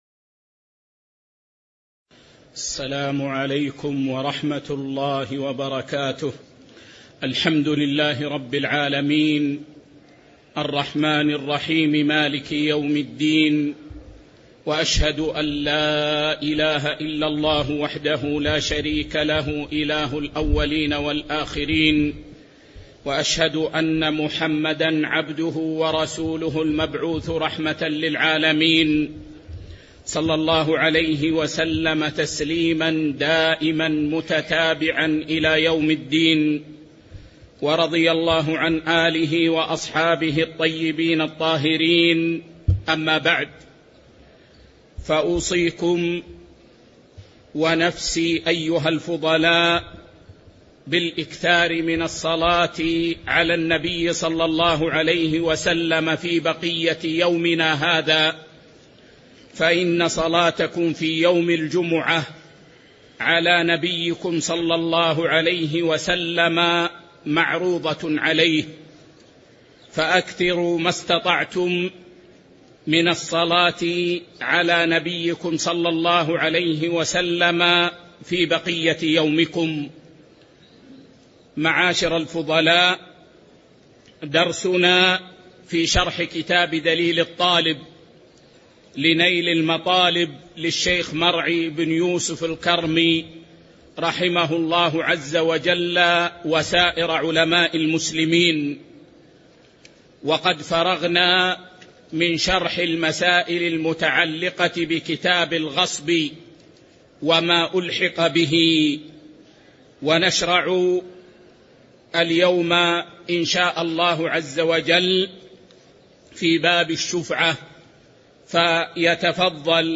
تاريخ النشر ٤ ربيع الأول ١٤٤٤ هـ المكان: المسجد النبوي الشيخ